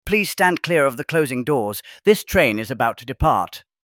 We deliver clear, professional, and neutral-sounding English announcements using a custom-trained AI voice that replicates the style of real British transport systems.
closing-doors.mp3